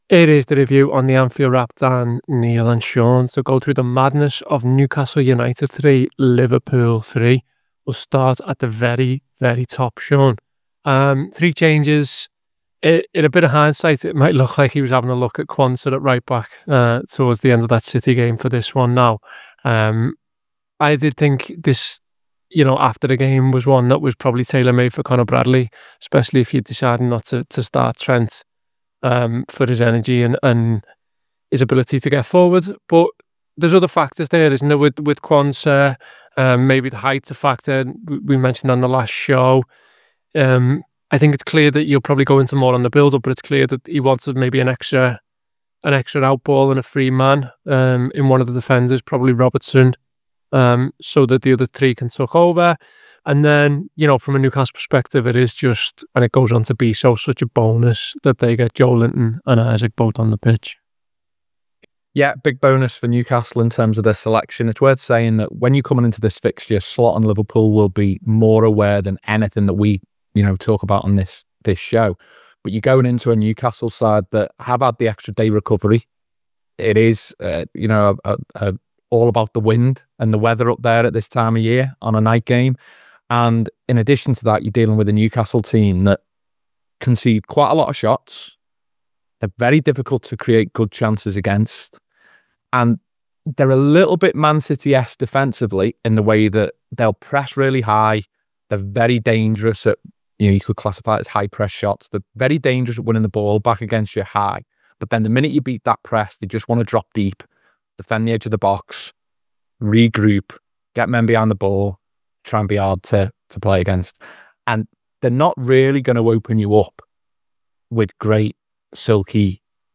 Below is a clip from the show- subscribe for more review chat around Newcastle 3 Liverpool 3…